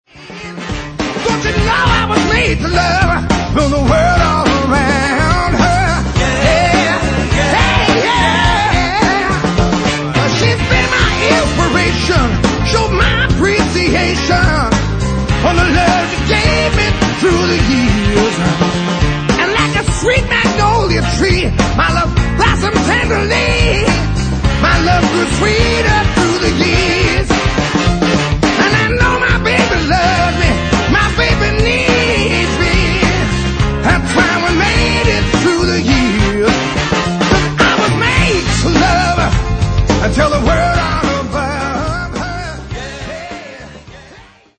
tribute album